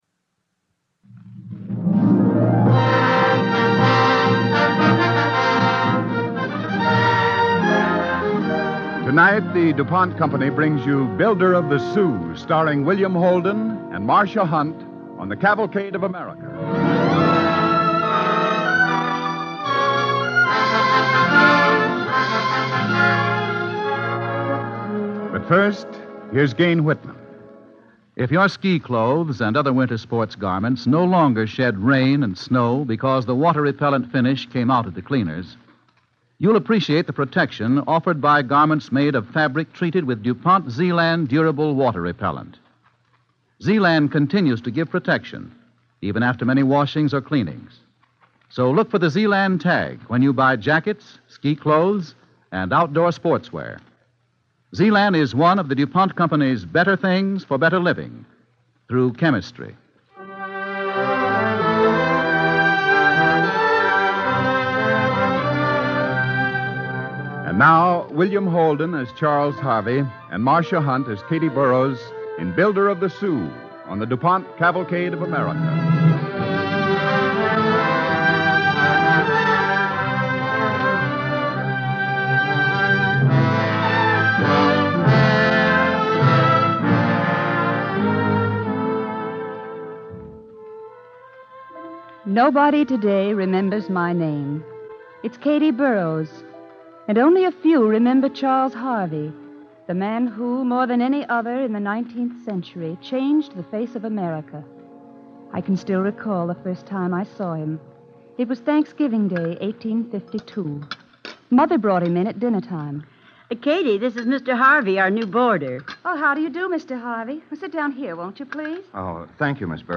Cavalcade of America Radio Program
The Builder of the Soo, starring William Holden and Marsha Hunt